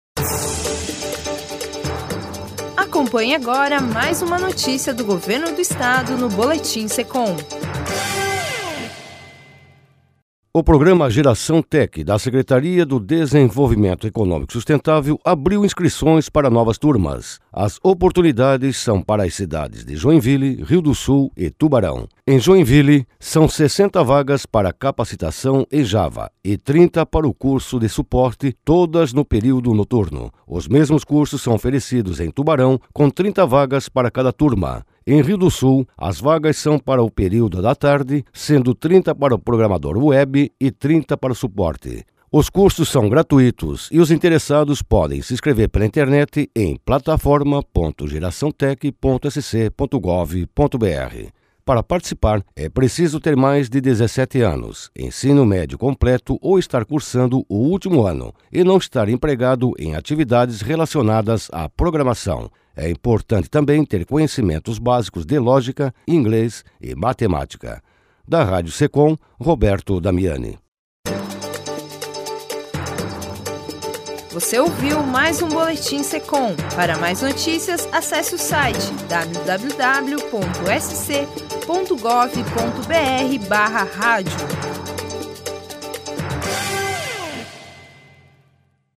3. Radio Secom